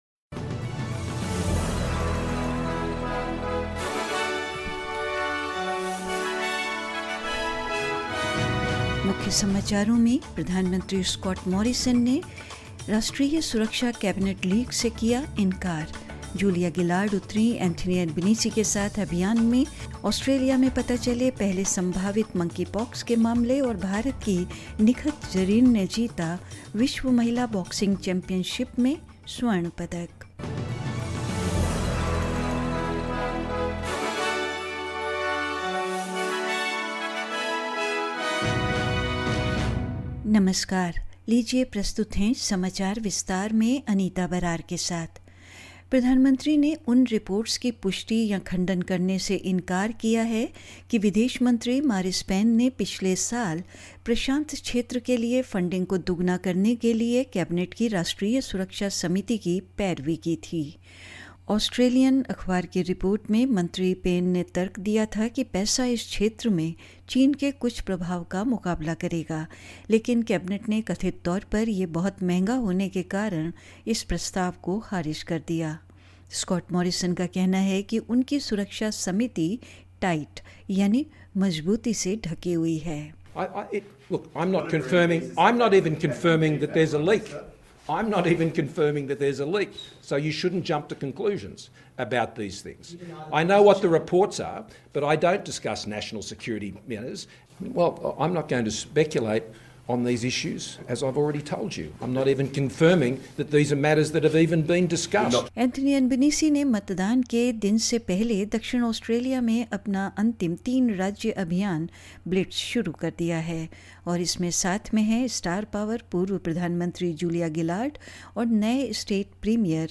In this latest SBS Hindi bulletin: Prime Minister Scott Morrison denies a national security cabinet leak, as Julia Gillard makes a rare campaign appearance alongside Anthony Albanese; Australia's first probable cases of monkeypox detected in Victoria and New South Wales; India's Nikhat Zareen won Gold Medal in the Women's World Boxing Championships in Turkey and more news.